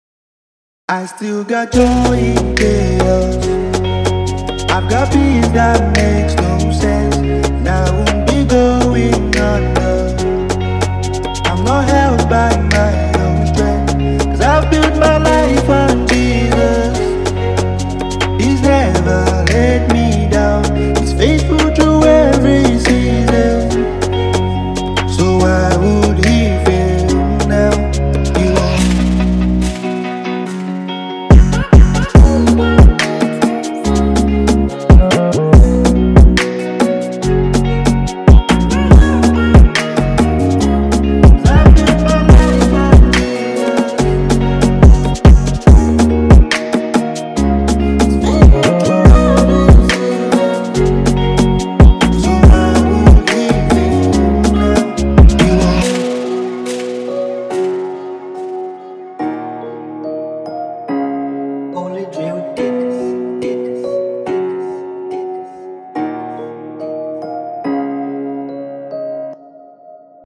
soul-lifting gospel song
” a soul-stirring gospel anthem from
Genre:Gospel